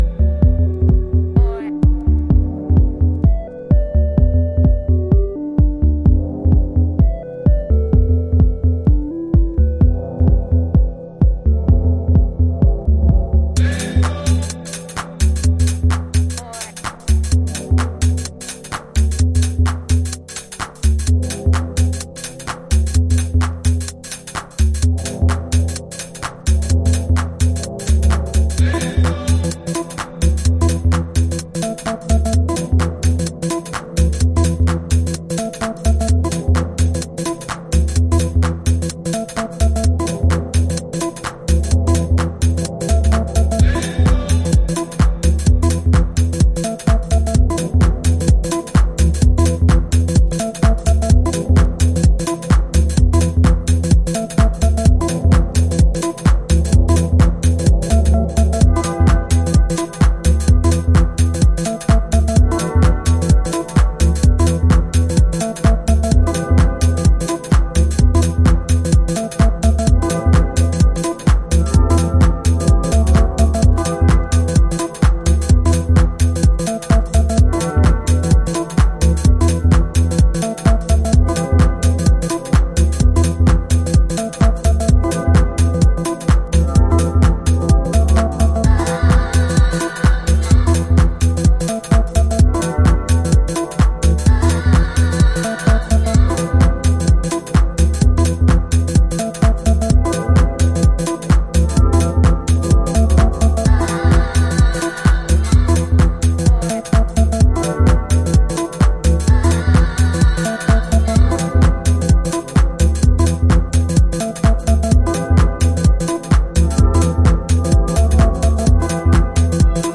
House / Techno